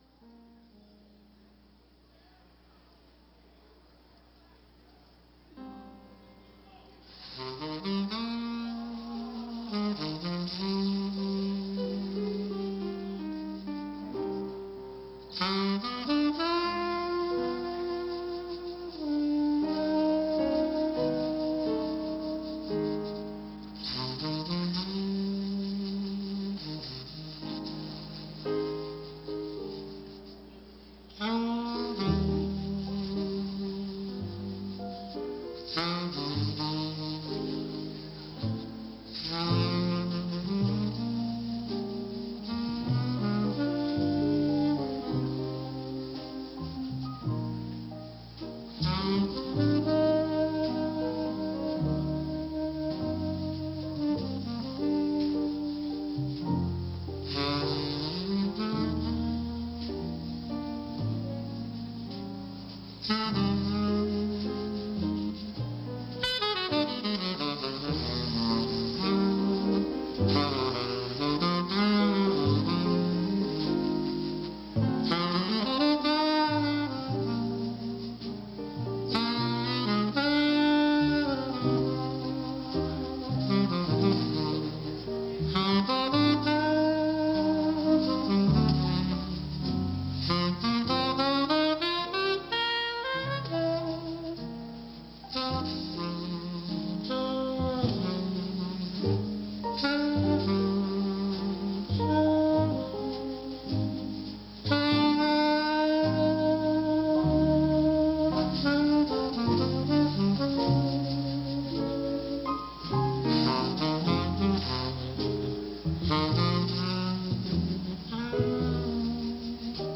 a delightful stew of hot Jazz